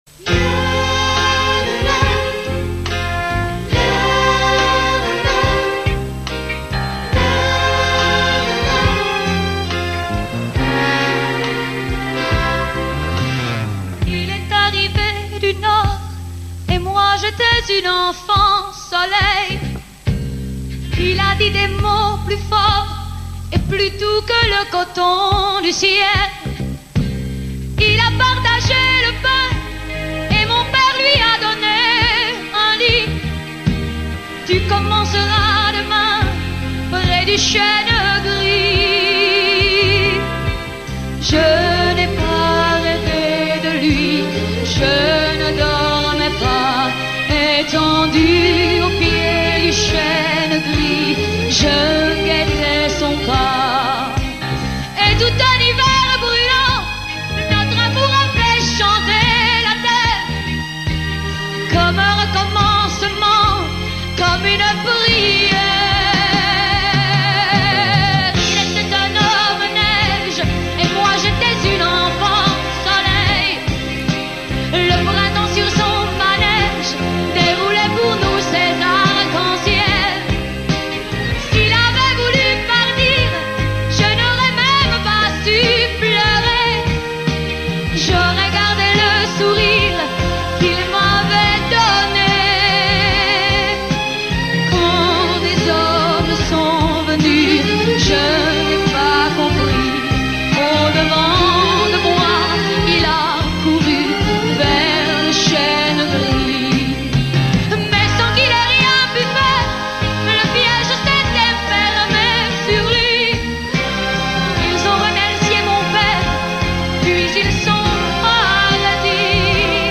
live version